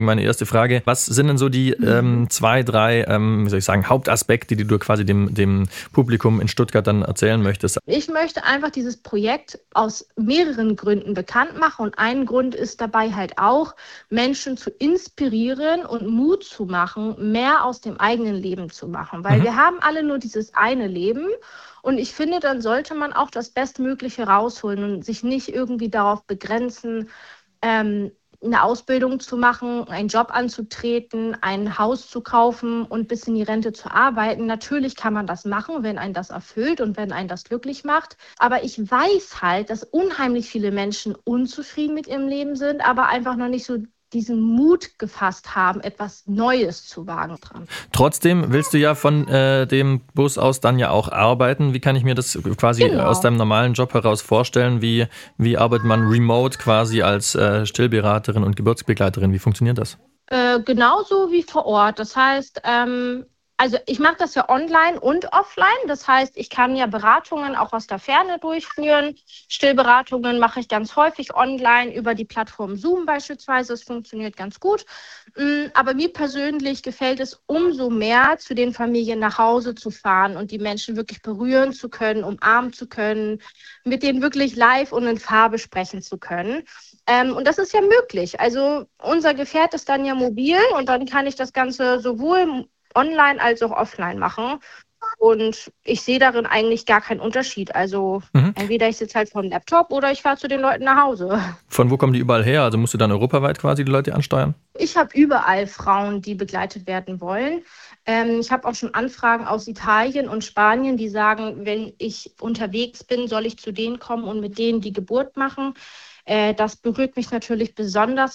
SWR-Interview